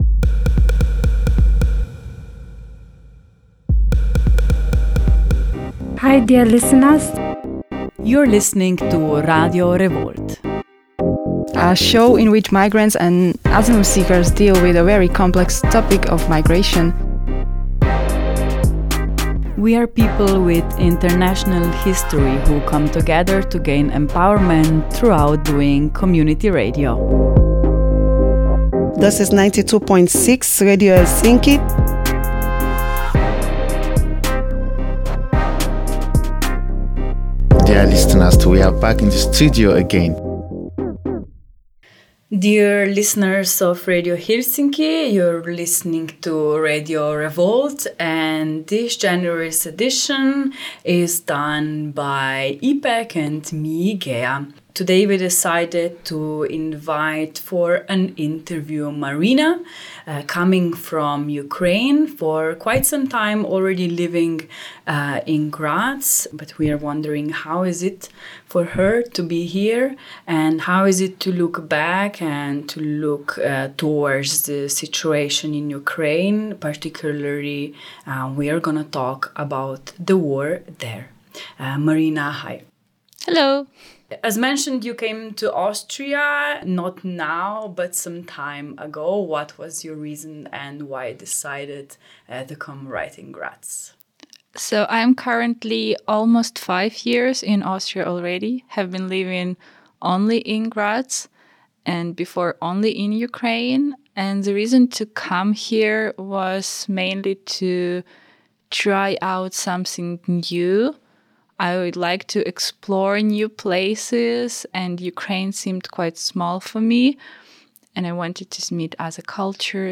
In the second part of the interview we open the topic of war in Ukraine and the role of Russia’s domestic politics in it.